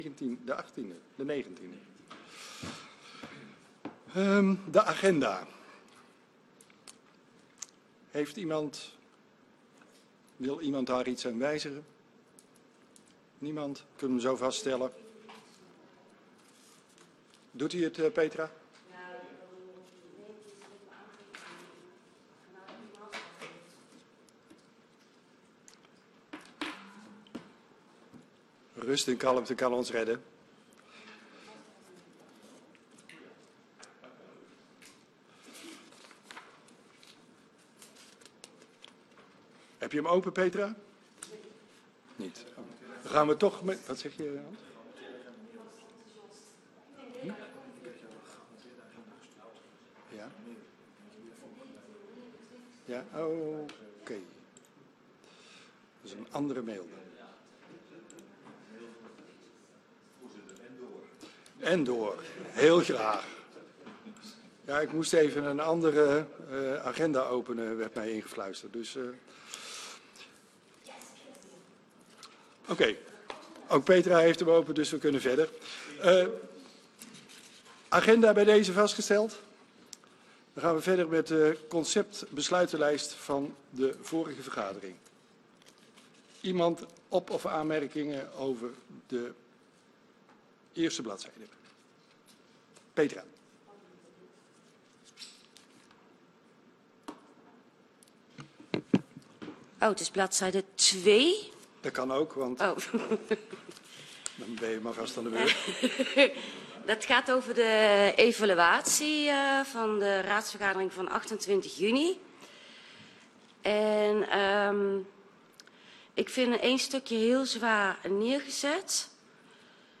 Download de volledige audio van deze vergadering
Locatie: Brandweerzaal